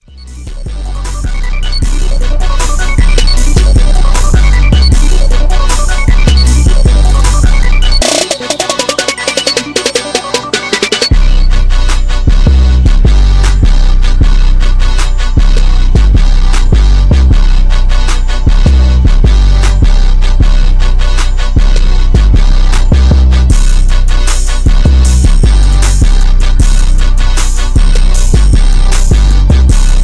rap beat